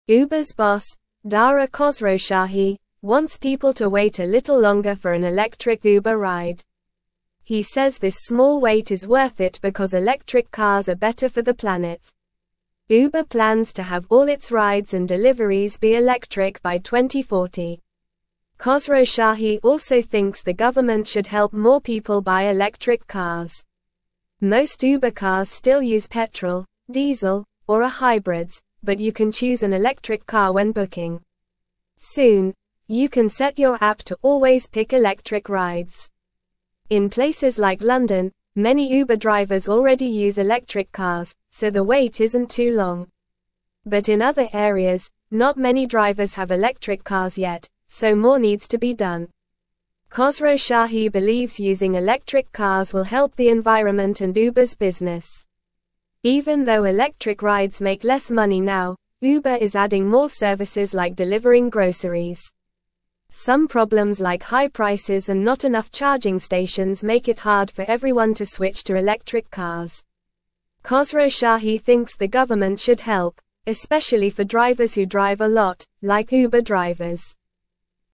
Normal
English-Newsroom-Lower-Intermediate-NORMAL-Reading-Uber-Boss-Wants-More-Electric-Cars-for-Rides.mp3